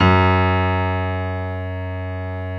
55p-pno09-F#1.wav